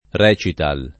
recital [re©it#l o
rital; ingl.